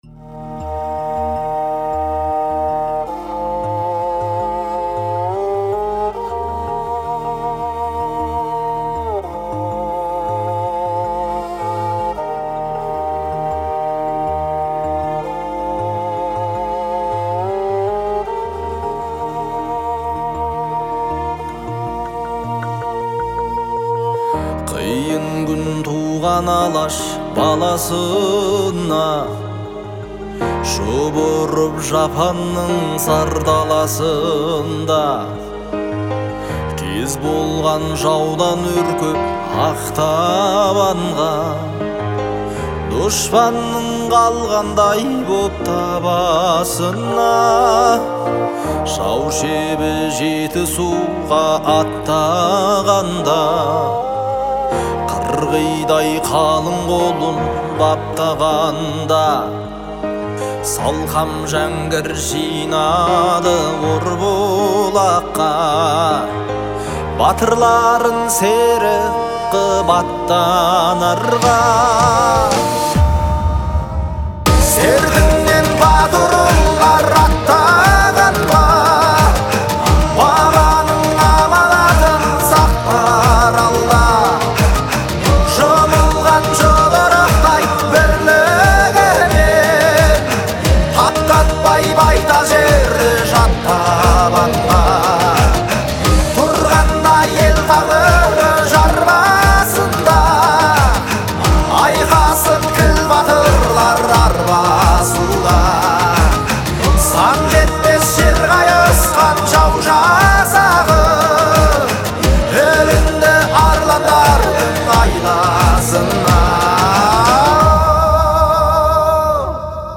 Исполненная с глубокими эмоциями